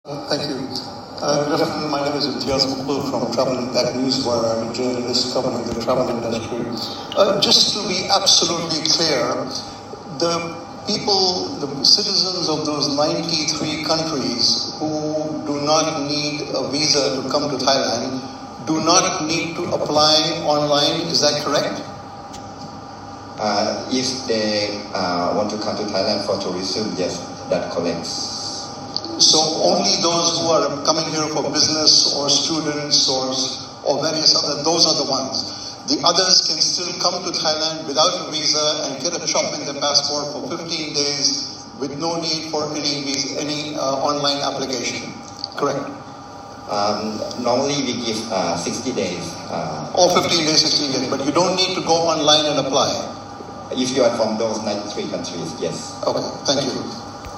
It took three questions to get this clarified at the E-Visa announcement briefing held by the Ministry of Foreign Affairs on 17 December.
The final question was asked by this reporter to establish unequivocal clarification about the visa-free access for tourism by citizens of 93 countries. Click on the link to listen to the audio of the question and the reply.